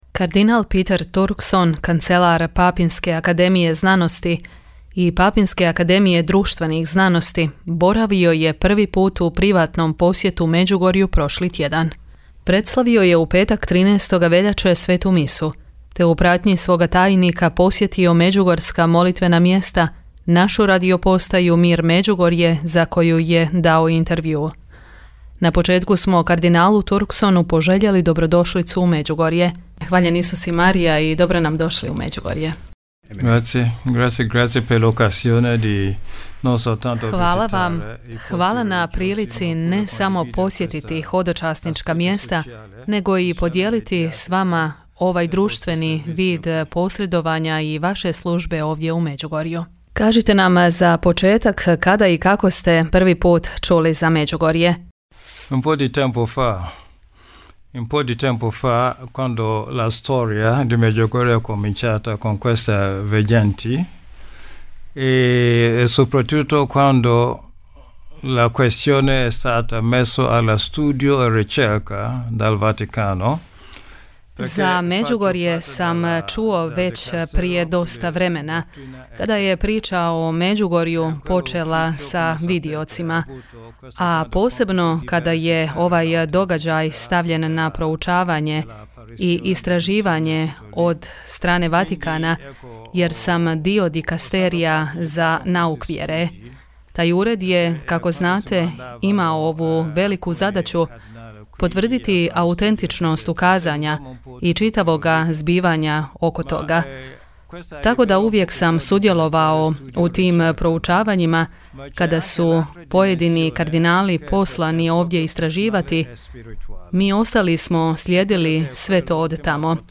INTERVJU – Kardinal Peter Turkson: Ljudi ovdje ponovno pronađu smisao i vjeru